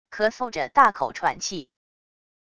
咳嗽着大口喘气wav音频